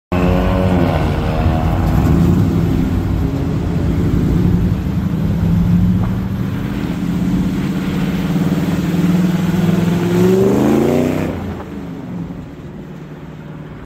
🔊 Supercharged American muscle gone way too spicy. A near miss, a lot of adrenaline, and that unmistakable Mustang roar.